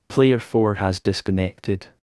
netplay_p4_disconnected.wav